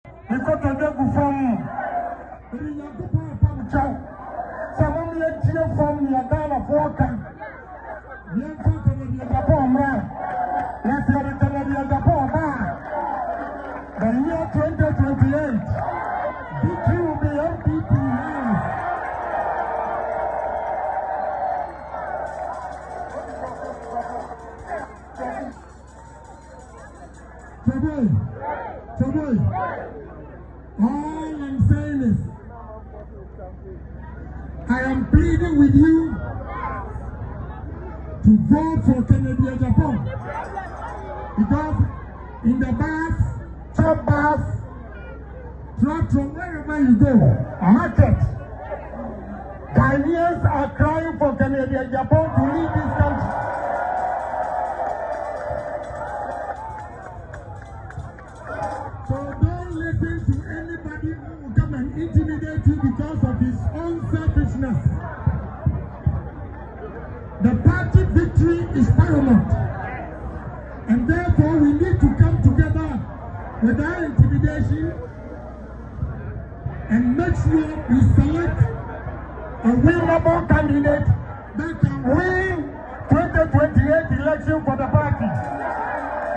Speaking after submitting his nomination forms at the NPP headquarters, the former Assin Central MP said, unlike other aspirants who said they have ideas, he has both “ideas and creativity” backed by a proven track record.